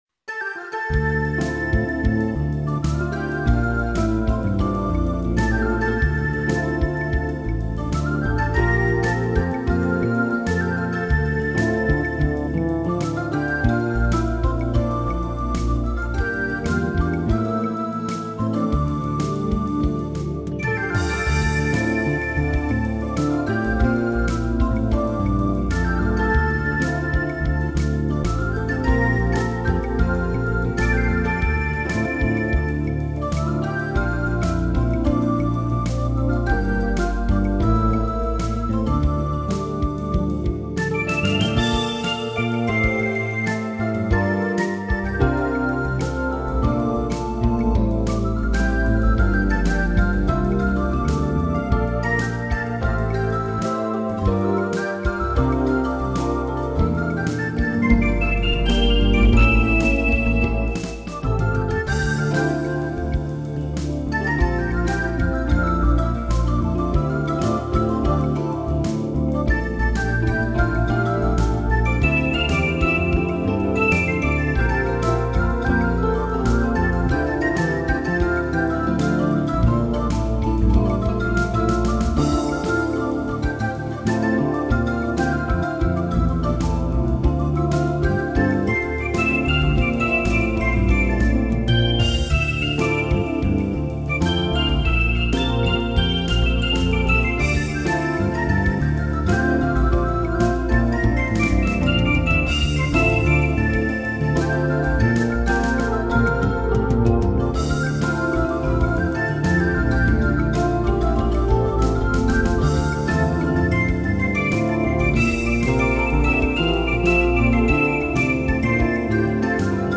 keyboardist